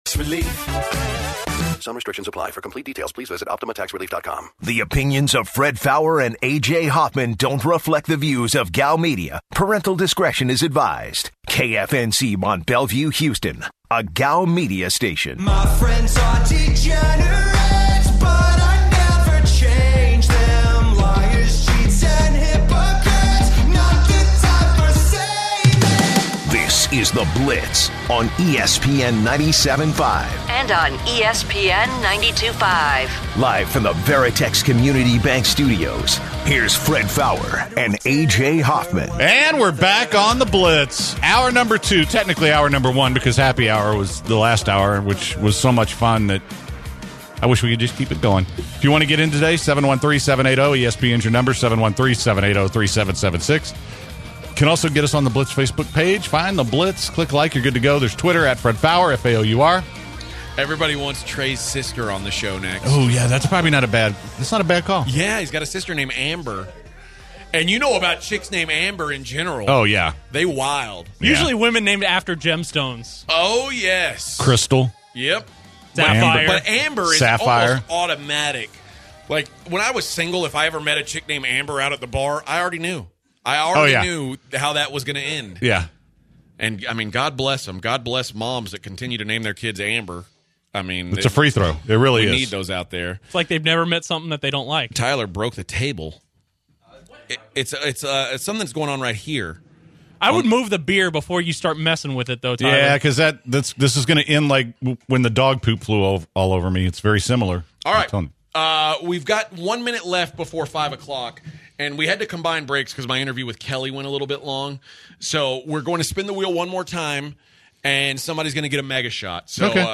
take calls from listeners and discuss NFL over/under games.